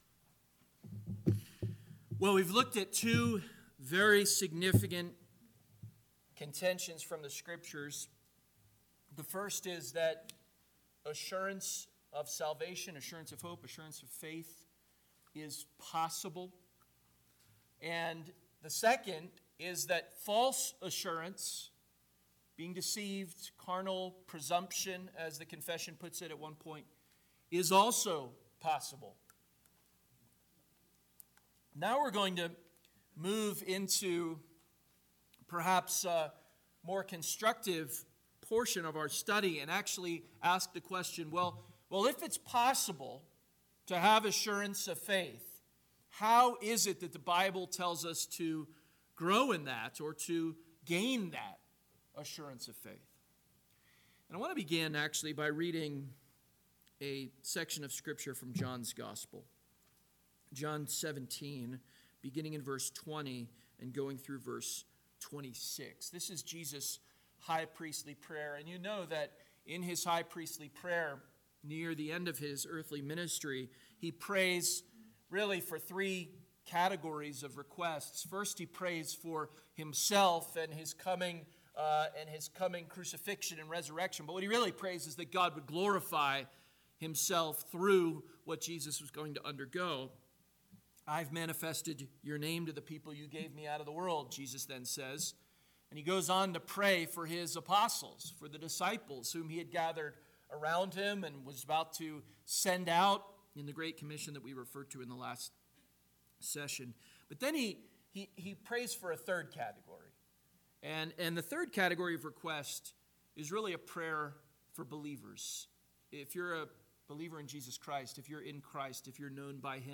2022 Cheyenne Reformation Conference Session 3 – Northwoods Sermons